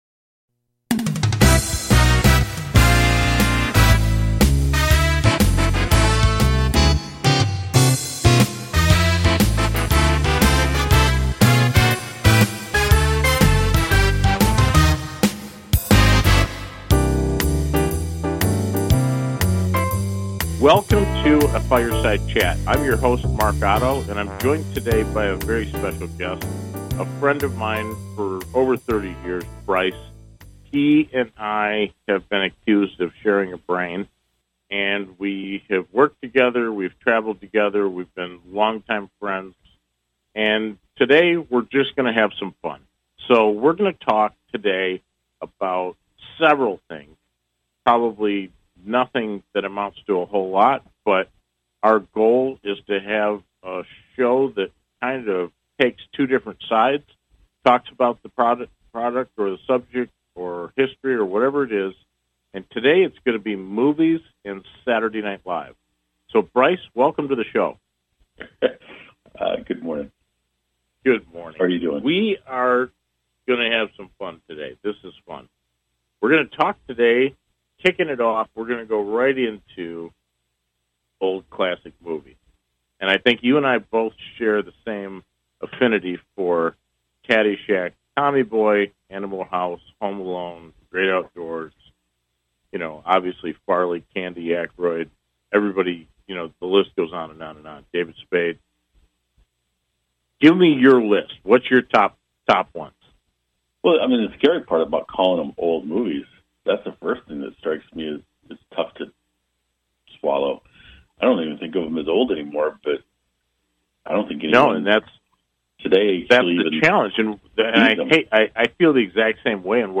Talk Show Episode
It is where SNL meets The Tonight Show; a perfect mix of talk and comedy.